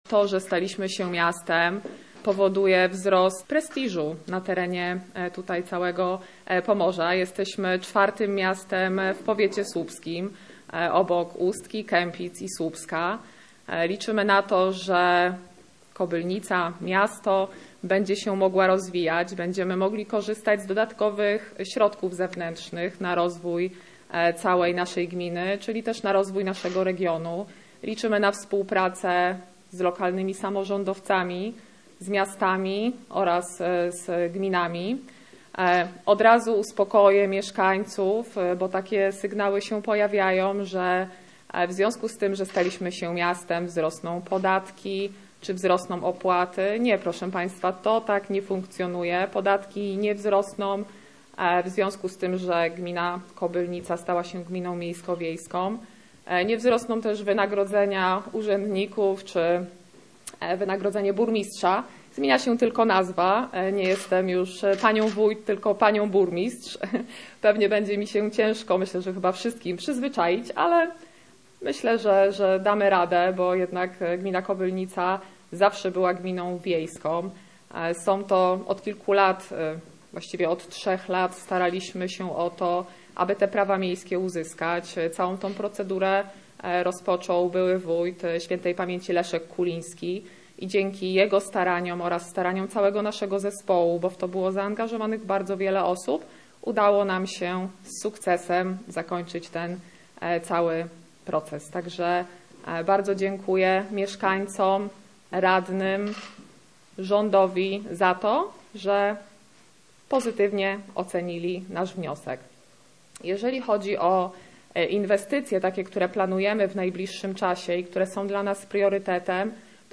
– Kobylnica stała się miastem dzięki pozytywnej opinii wojewody pomorskiej i rozporządzeniu premiera – mówiła na pierwszej konferencji prasowej burmistrz Anna Gliniecka-Woś.
kobylnica_konfa_14_33.mp3